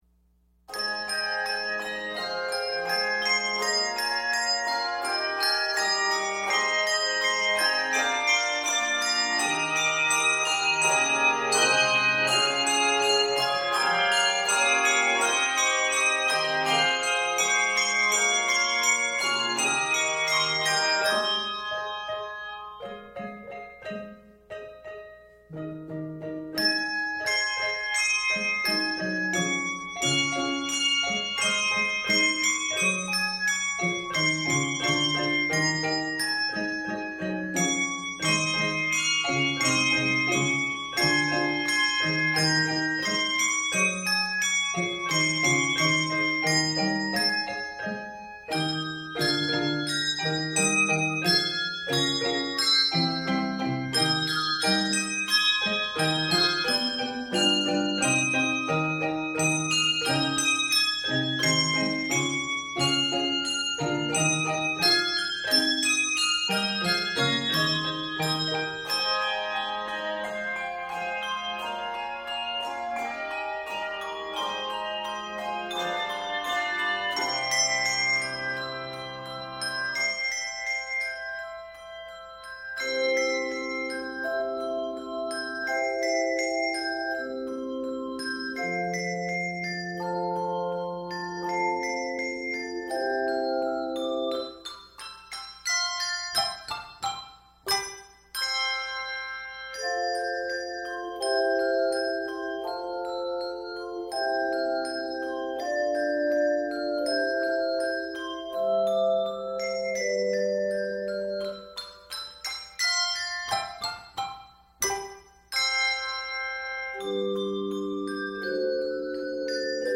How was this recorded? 2013 Season: Easter